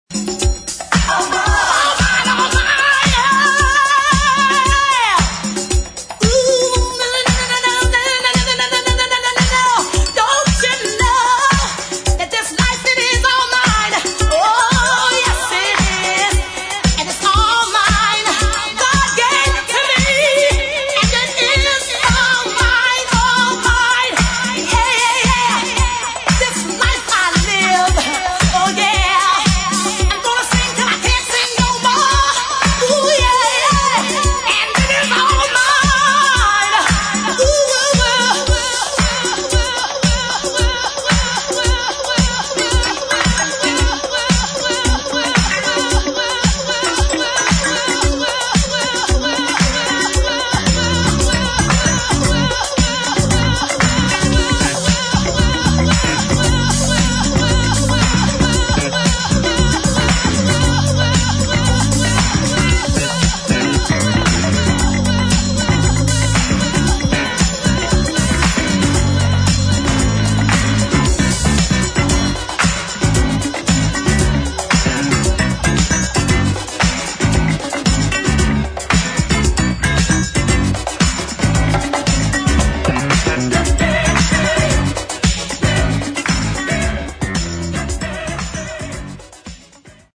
[ DEEP HOUSE | DISCO ]